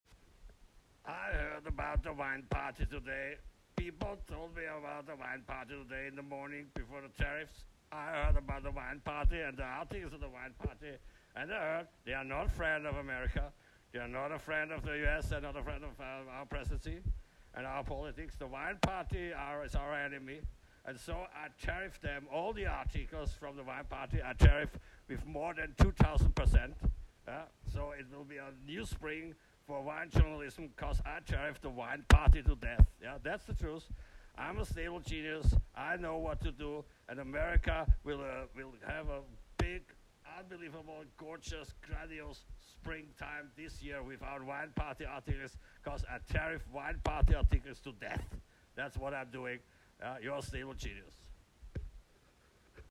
President Stabile Genius announces new tariffs against German-Austrian-Site: “The Wineparty”. “They are not friends of the US”, he said in front of the media.